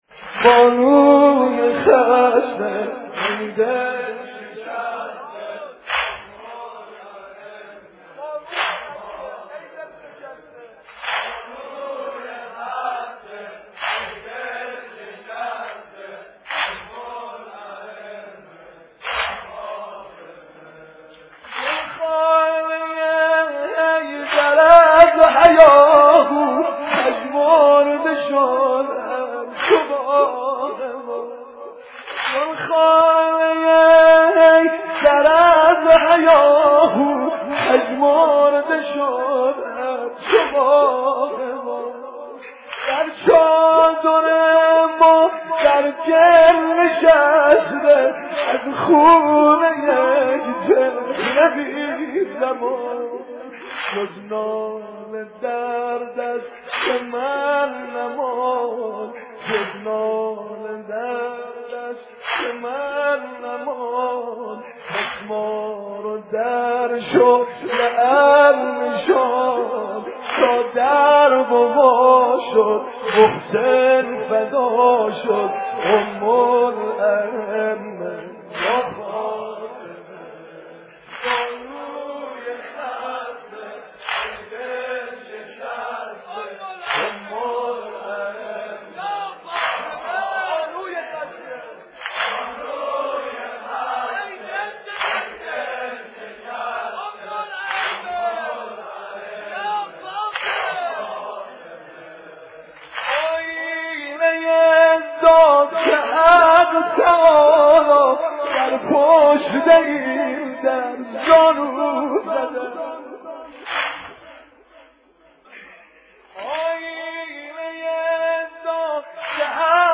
دانلود مداحی شهادت حضرت زهرا (س) ایام فاطمیه